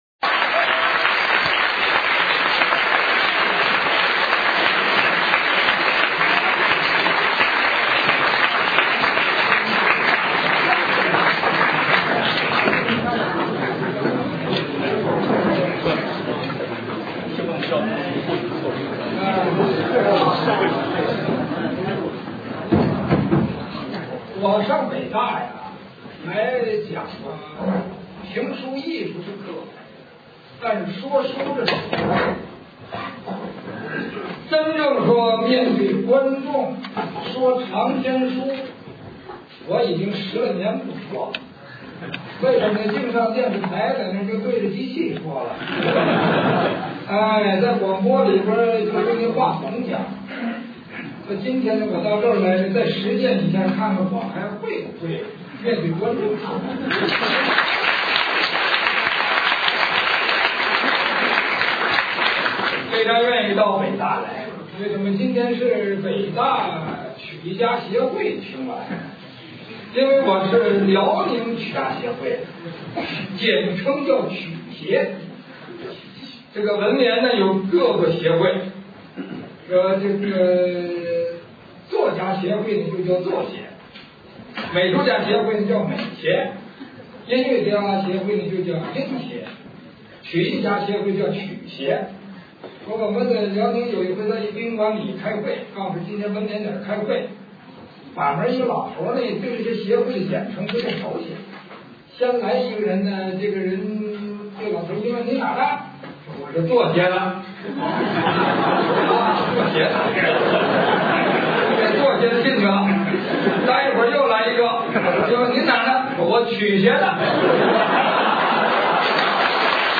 田连元评书_潘杨讼01.mp3